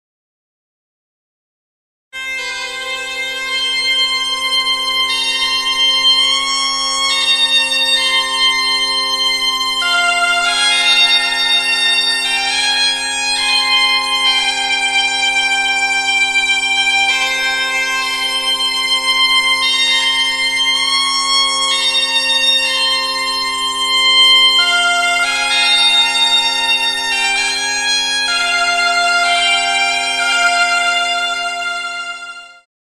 Si estás buscando gaitero para boda, El gaitero de Madrid,gaiteros, música celta, música irlandesa para bodas, gaiteros para bodas
Música tradicional